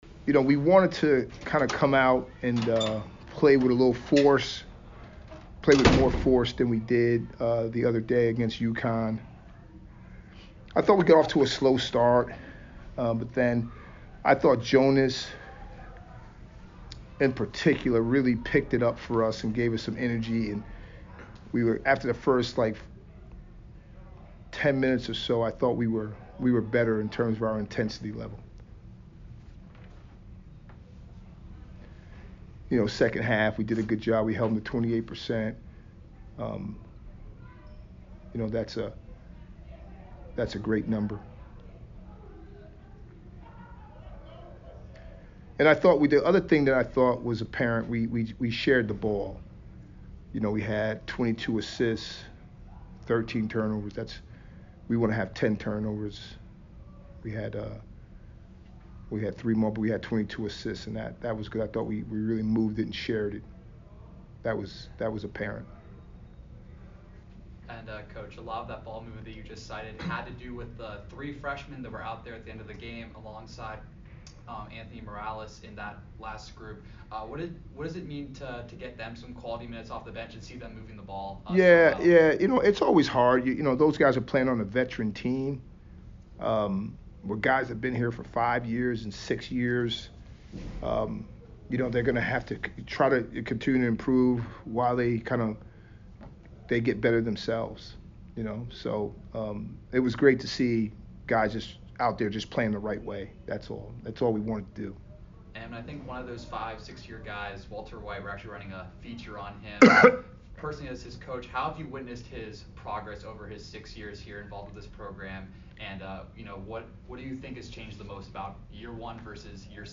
JWU Postgame Interview